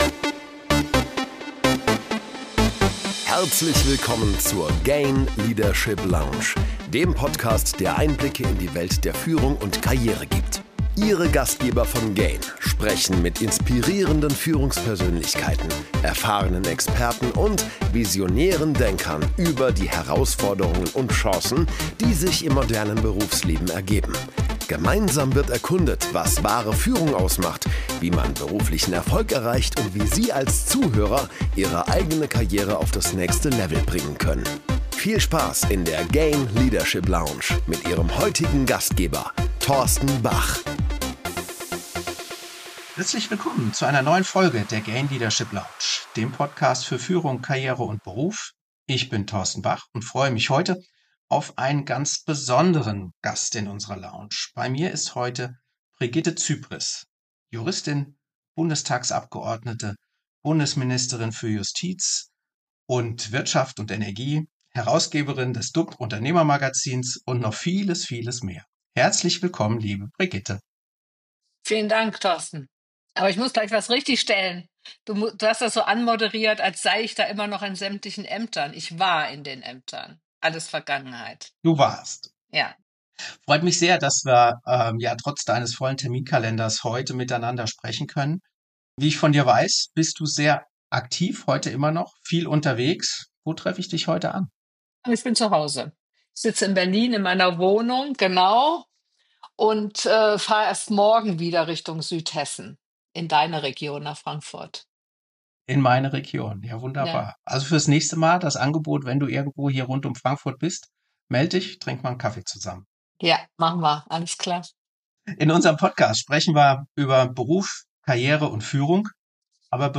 Interview, Karriere, Führung, Leadership, GAIN, Brigitte Zypries, Politik, Unternehmertum, Digitalisierung